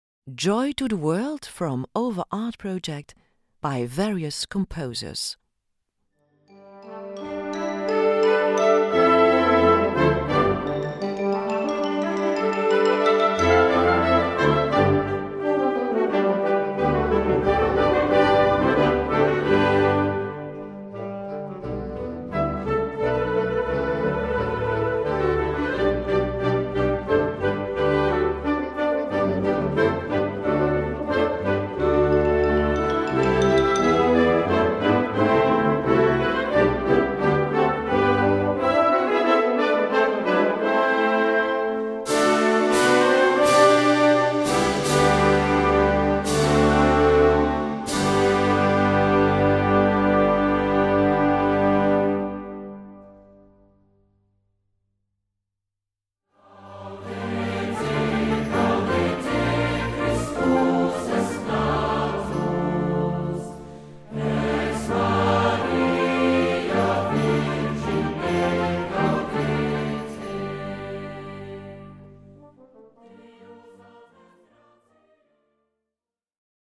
Concertband & Choir